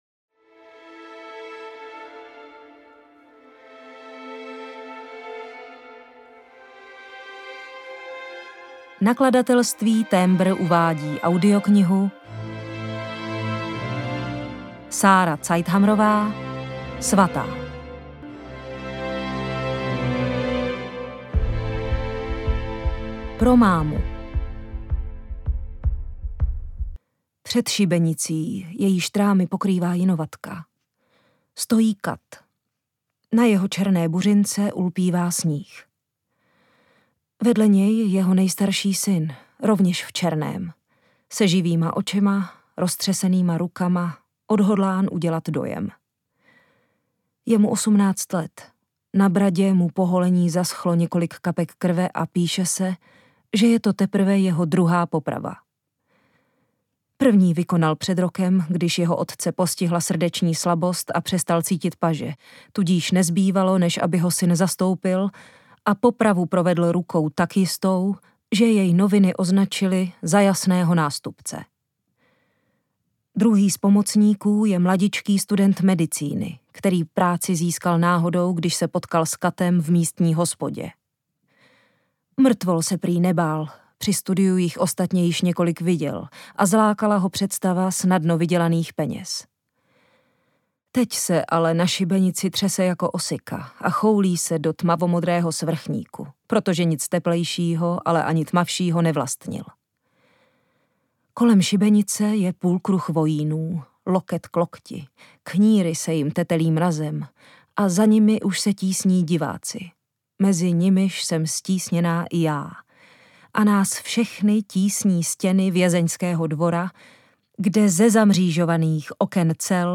Ukázka z knihy
Čte Zuzana Kajnarová
Natočeno ve studiu S Pro Alfa CZ